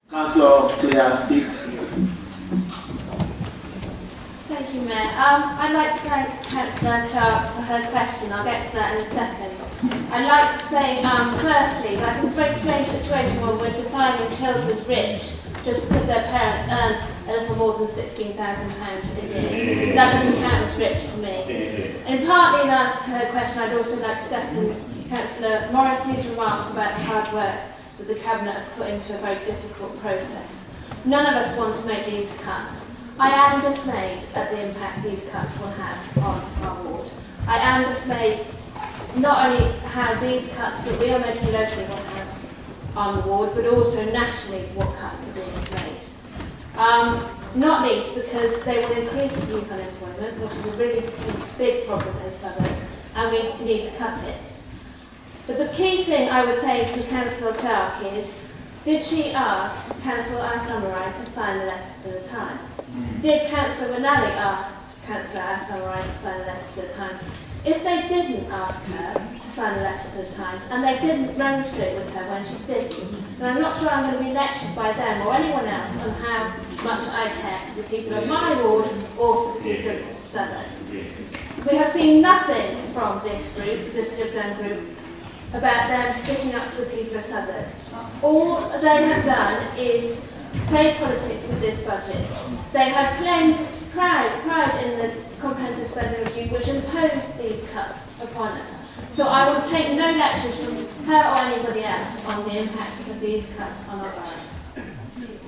Cllr Claire Hickson's speech in Southwark budget debate